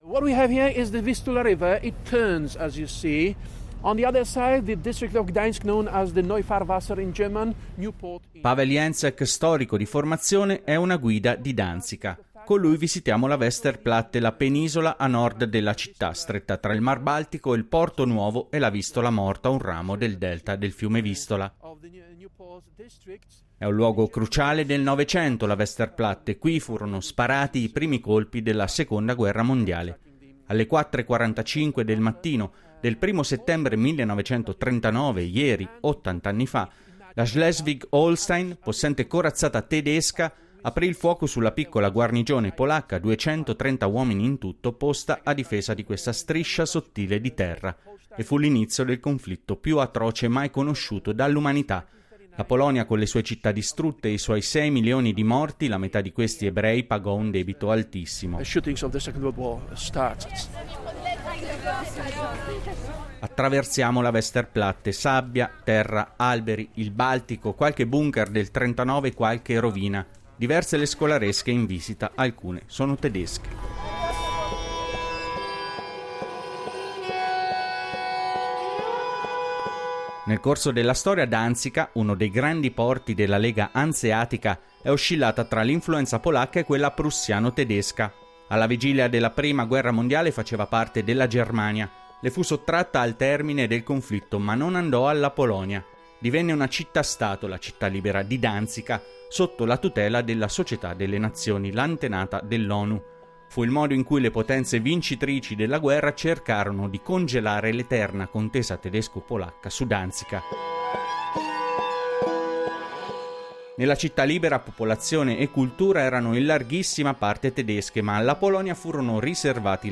In questo Laser vi portiamo a Danzica, con sopralluoghi nei posti in cui la Storia irruppe nella città; visite a musei di Solidarnosc e della Seconda guerra mondiale; interviste con reduci di Solidarnosc e intellettuali. Per capire come la memoria viene trattata, e come influenza il dibattito politico della Polonia odierna.